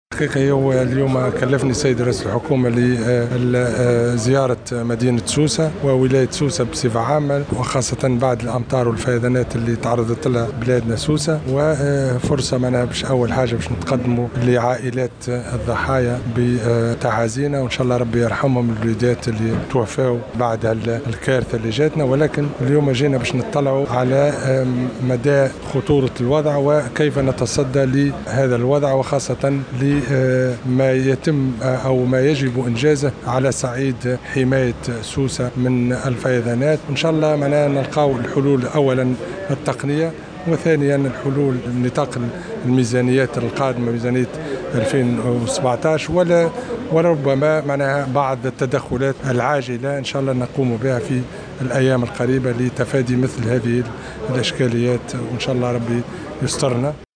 وأعلن العرفاوي في تصريح لمبعوث الجوهرة أف أم أنه سيتم اجراء بعض التدخلات العاجلة في الأيام القادمة مضيفا أنه سيتم البحث عن حلول تقنية لمجابهة الفيضانات وفق ما تسمح به ميزانية 2017 وفق تعبيره.